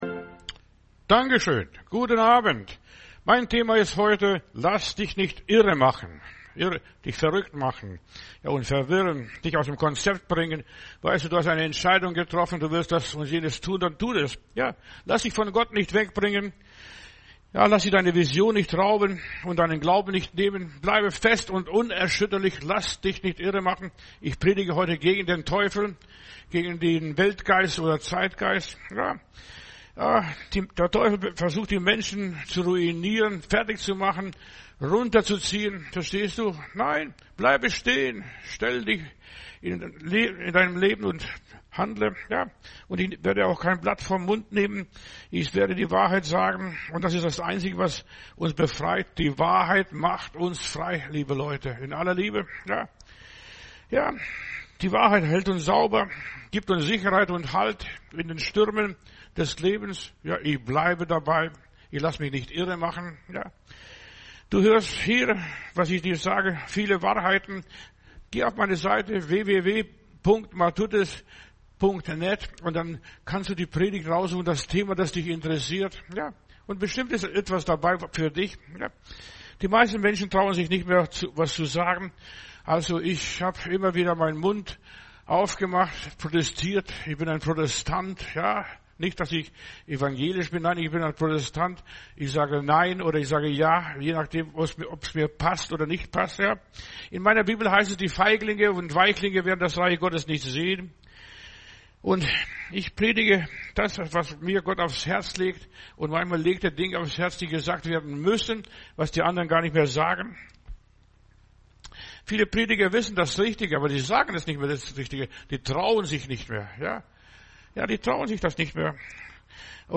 Predigt herunterladen: Audio 2025-01-17 Lass dich nicht irremachen Video Lass dich nicht irremachen